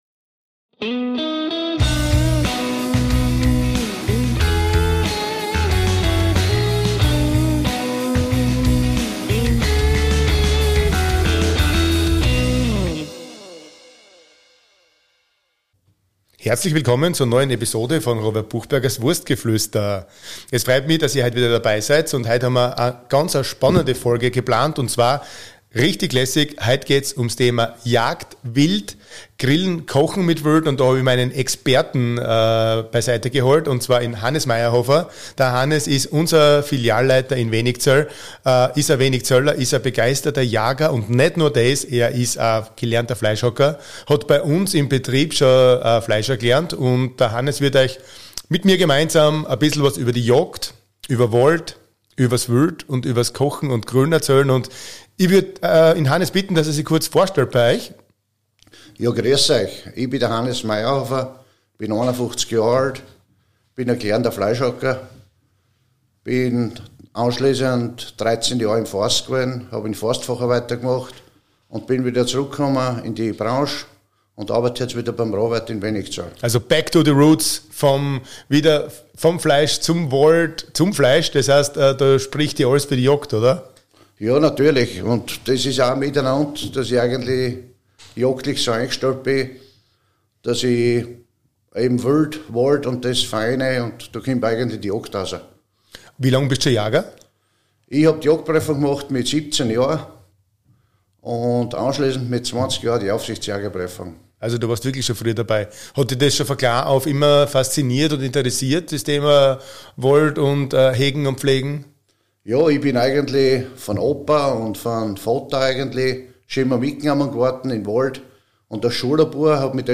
Er ist leidenschaftlicher Jäger und erfahrener Fleischhacker. Es geht um das spannende Thema Jagd und die Verarbeitung von Wildfleisch in der Küche.